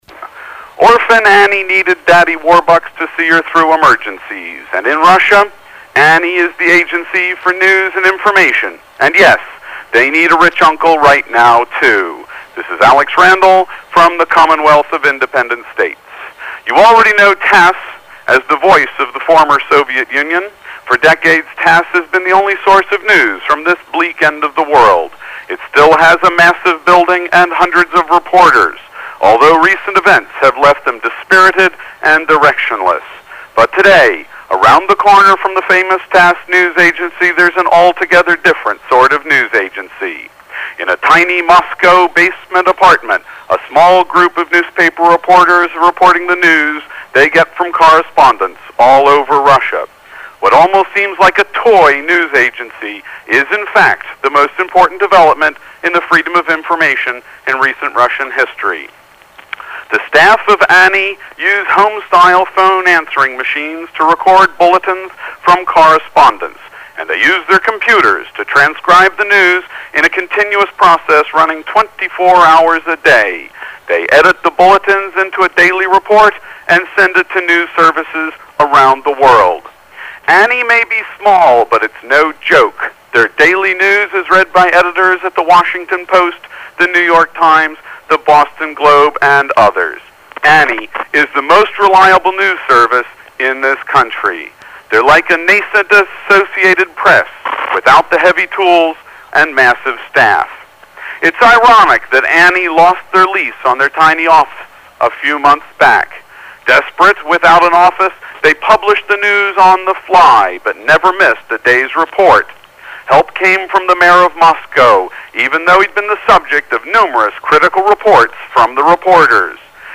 This is a live report – from Moscow.